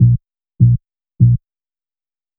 FK100BASS1-L.wav